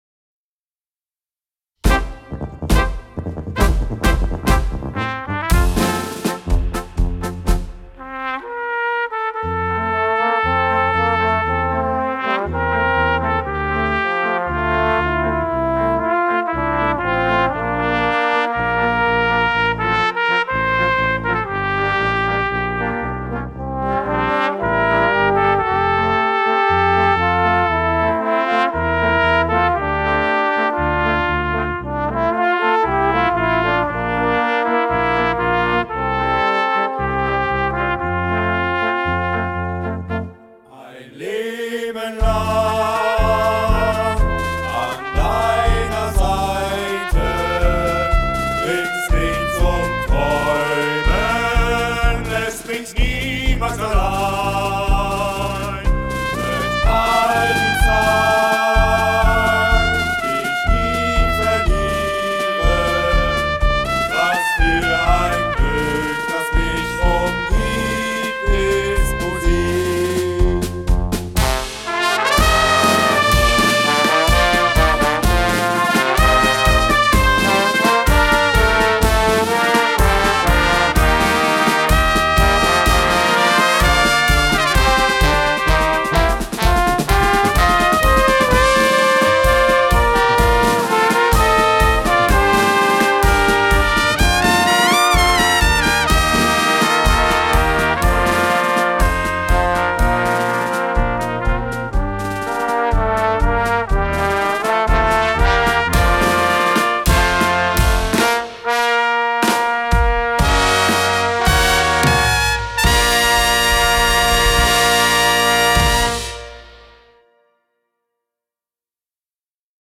Weil Brass mehr kann...
Brass mit Herz und Seele.